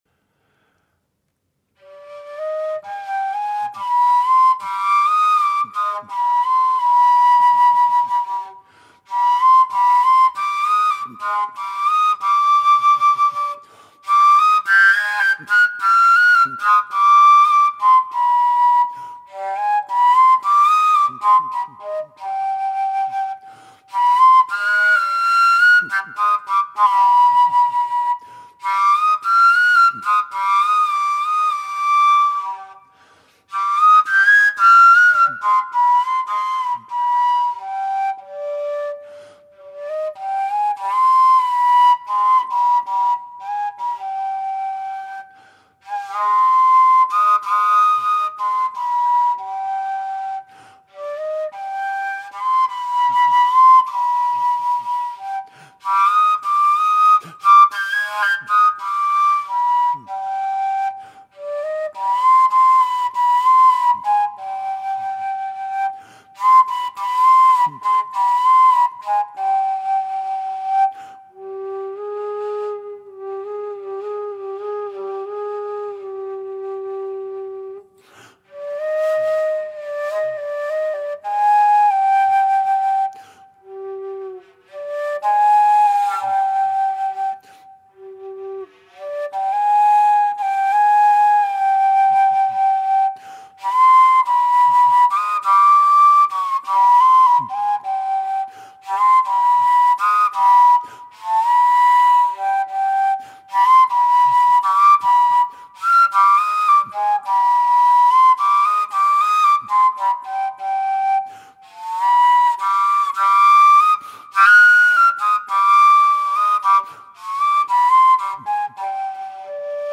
Harmonic Overtones Flutes
Listen to low G improvisation (:audioplayer